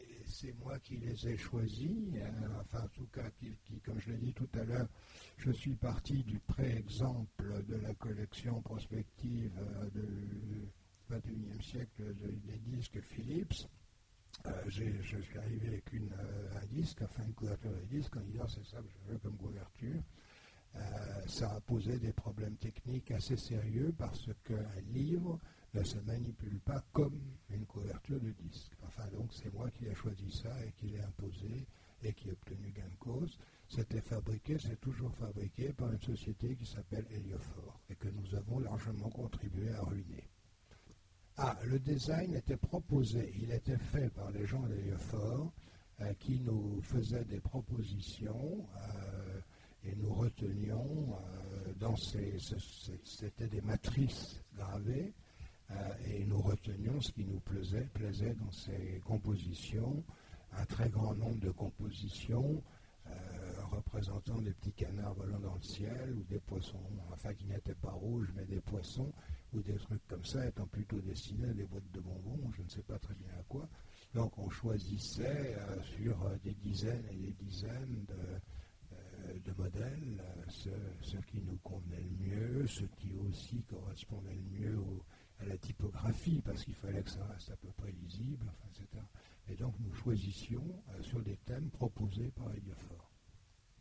Voici l'enregistrement de la conférence avec Gérard Klein aux rencontres de l'Imaginaire de Sèvres du 12 décembre 2009 à l'occasion des 40 ans d'Ailleurs et demain.
Les questions du public: